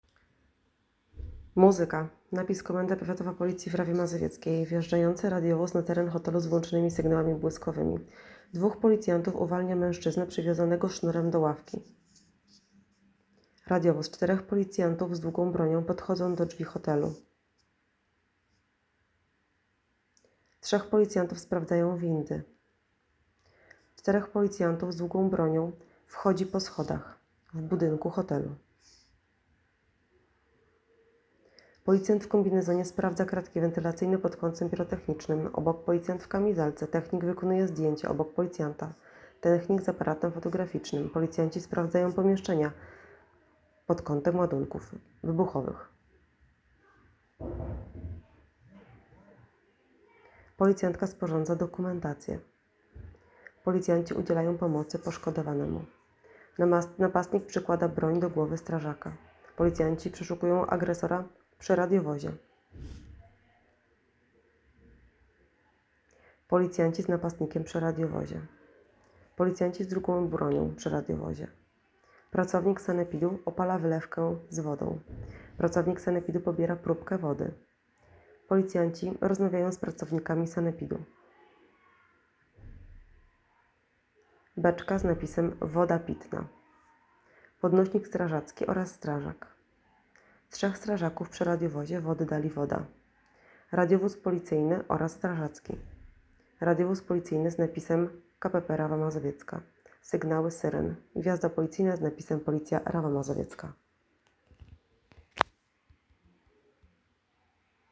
Nagranie audio audiodeskrycja filmu.m4a